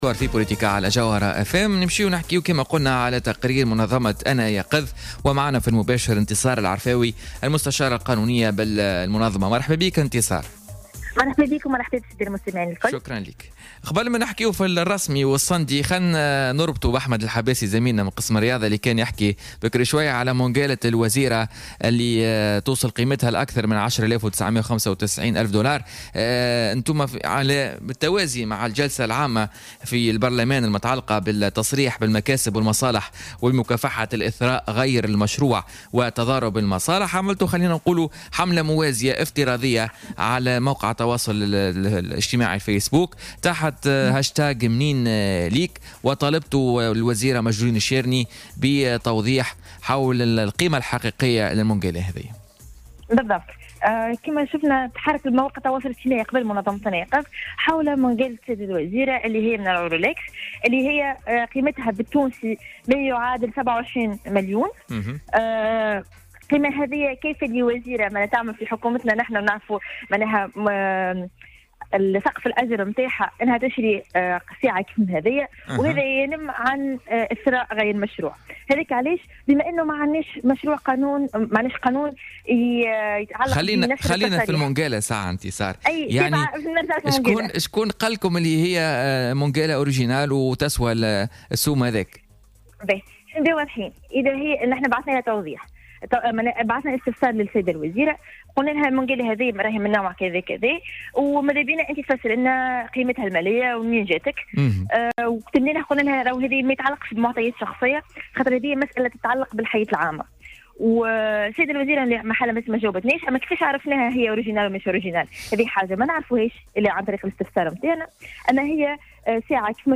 مداخلة هاتفية مع "بوليتيكا" على "الجوهرة أف أم"